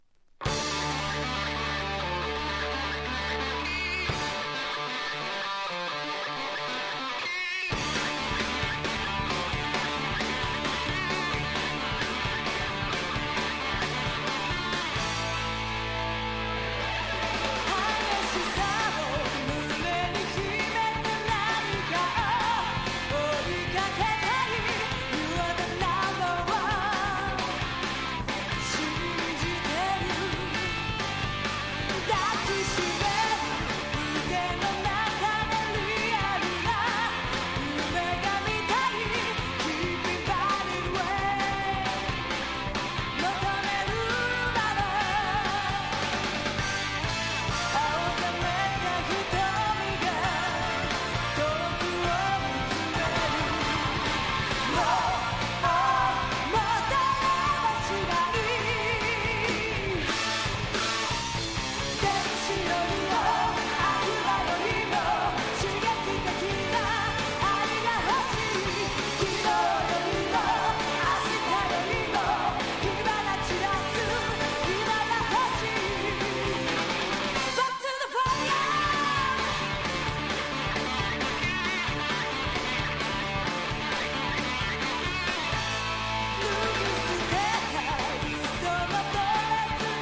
ヘヴィメタルを強く意識した代表曲の1つ。'
60-80’S ROCK